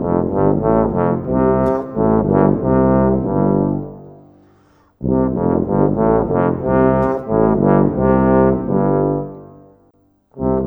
Rock-Pop 22 Horns _ Tuba 01.wav